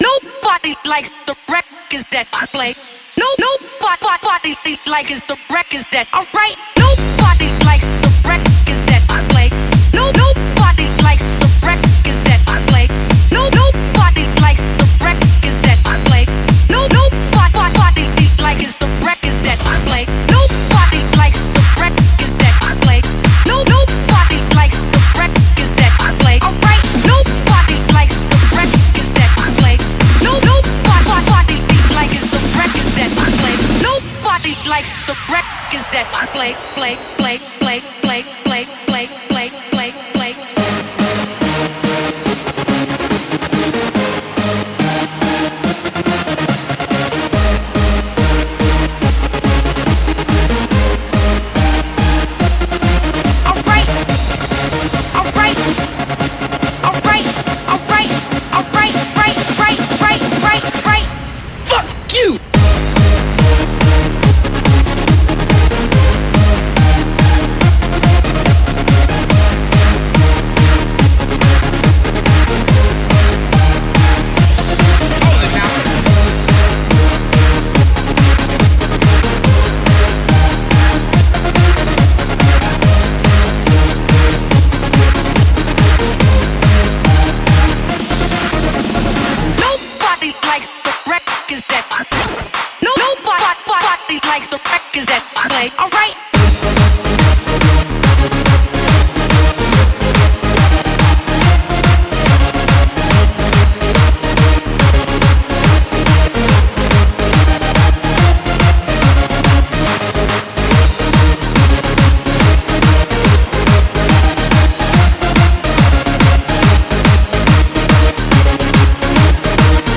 BPM142
Audio QualityPerfect (Low Quality)